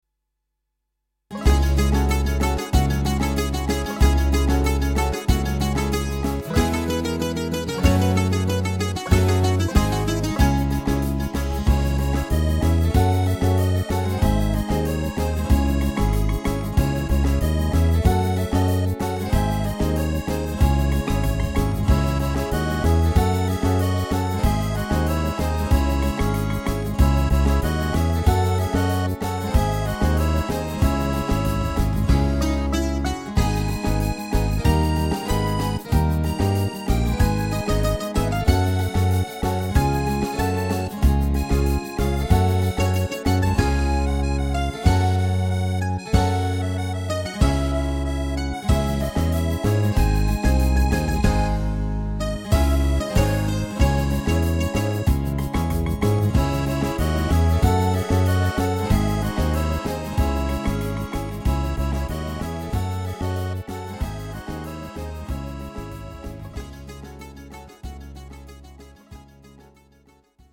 Greek Dance SyrtoRumba-Bagio